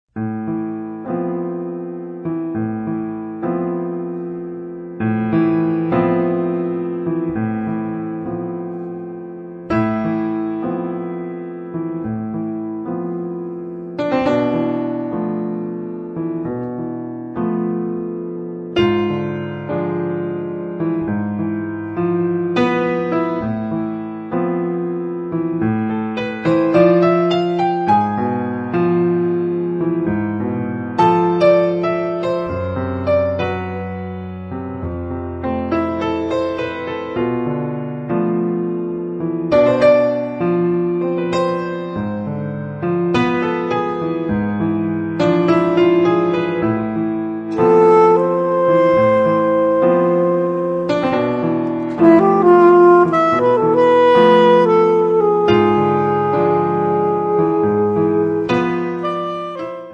sax sopranino, Sax Baritono
pianoforte